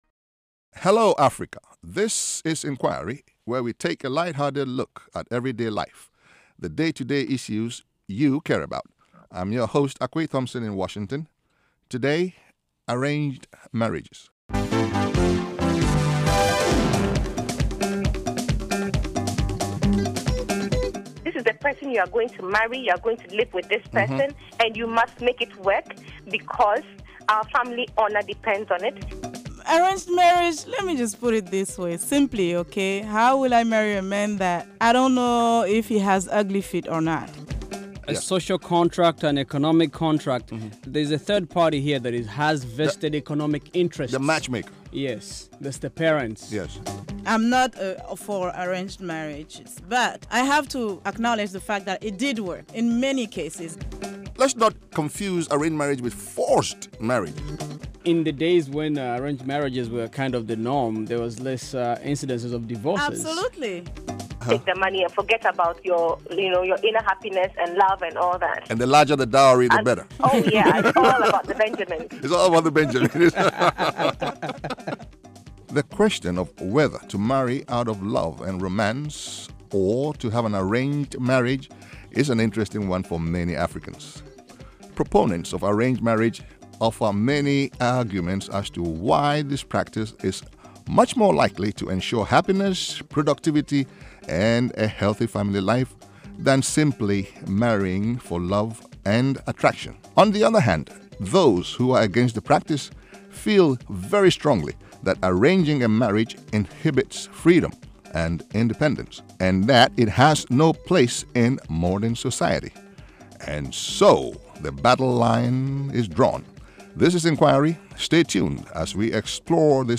a light-hearted, laughter-filled show that takes a look at everyday life - everything from where to find the best street food, to African comedy and storytelling, to the impact of cell phones on modern life.